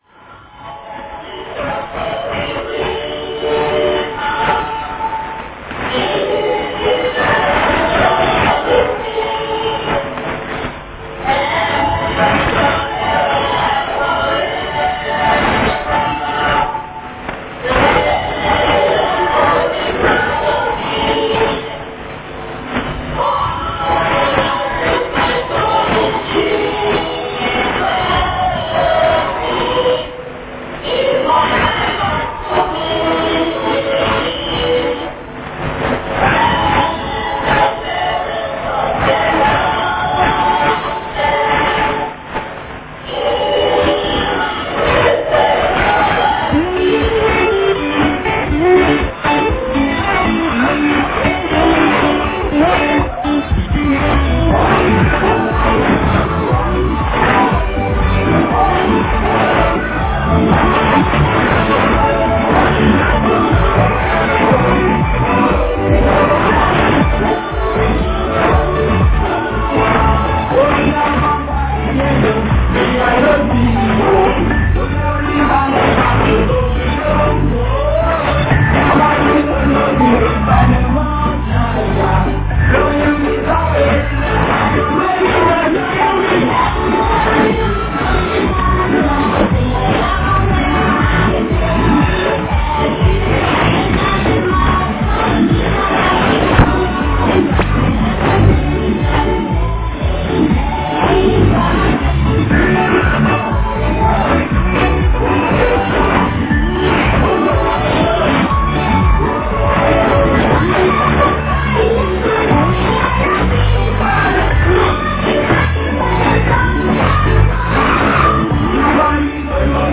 <受信地：埼玉県戸田市 荒川河川敷 RX:ICF-SW7600GR ANT:AN-12>
※03:06-03:54 男性放送終了アナウンス
※03:54-05:01 ♪バヌアツ国歌